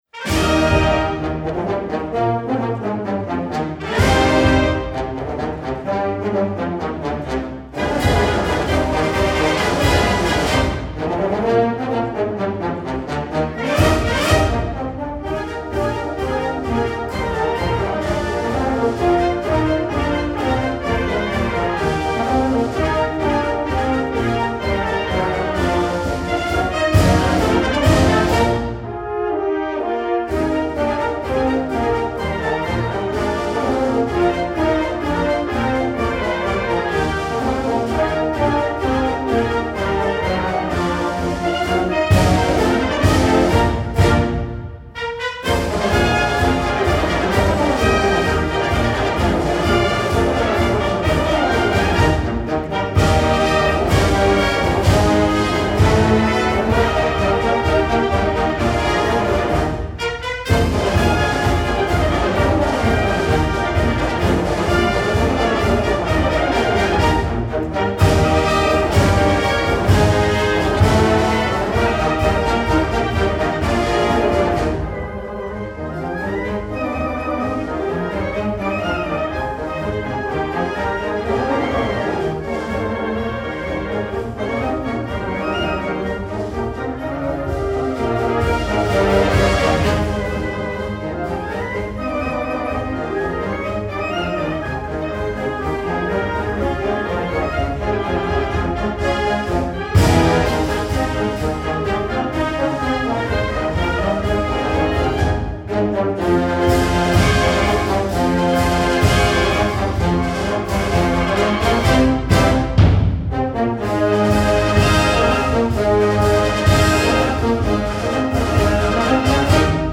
The Texas A&M University Wind Symphony
FOUR CONTRASTING MARCHES (Audio Only)